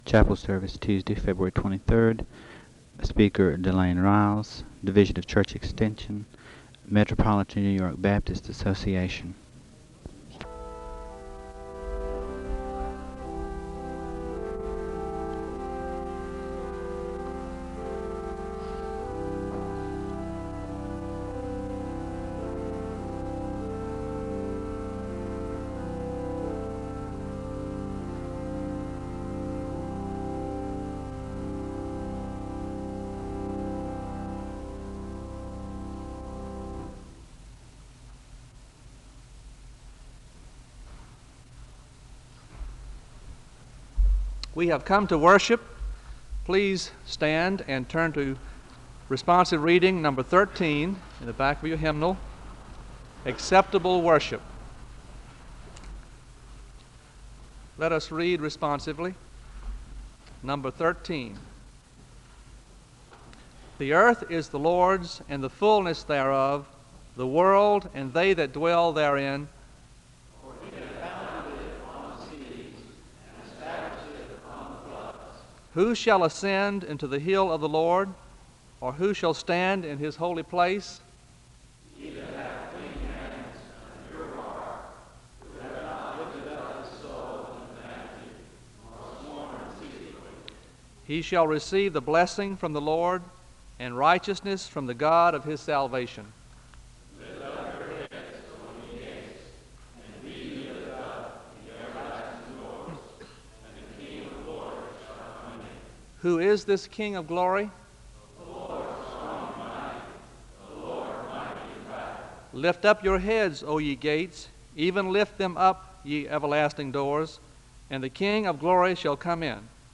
The service begins with organ music (00:00-00:40). The speaker leads in a responsive reading (00:41-02:32). The speaker gives a word of prayer (02:33-04:19).
The choir sings a song of worship (05:53-10:05).
Location Wake Forest (N.C.)